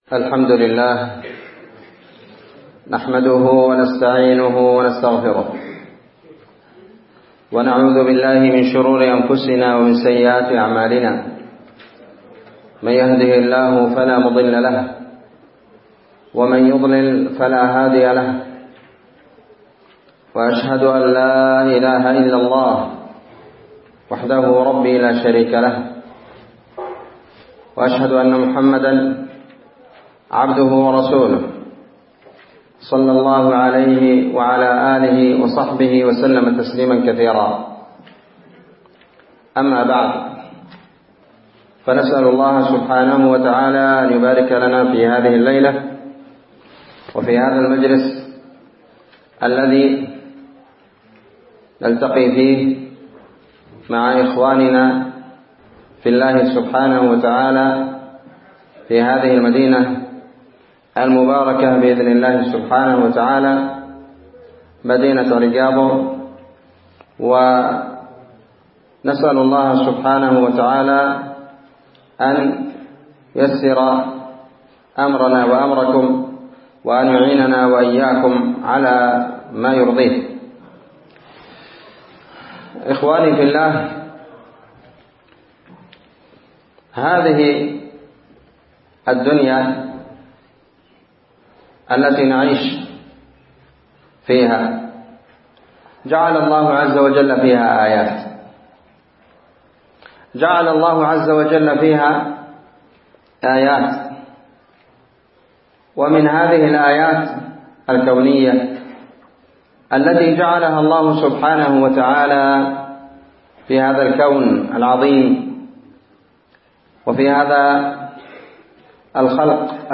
محاضرة بعنوان بعض أحكام الشتاء وحِكَمه ١٣ جمادى الآخرة ١٤٤٤